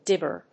音節dib・ber 発音記号・読み方
/díbɚ(米国英語), díbə(英国英語)/